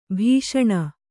♪ bhīṣaṇa